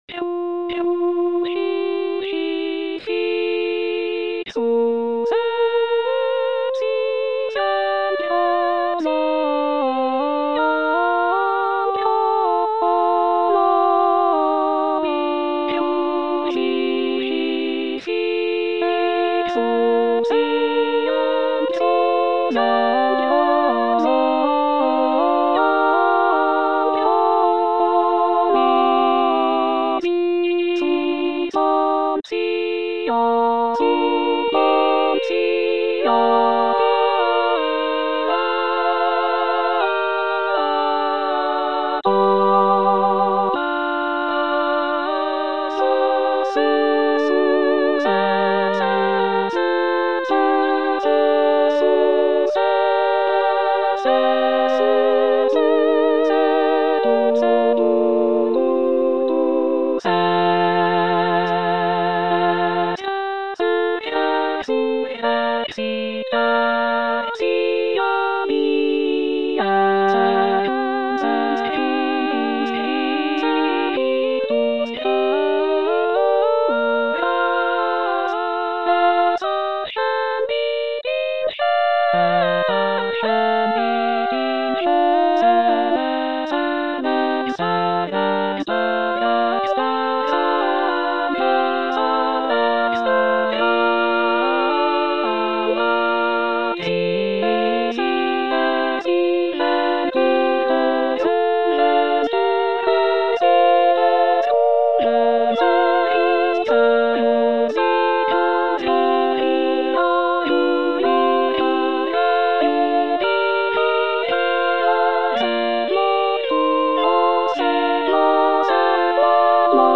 All voices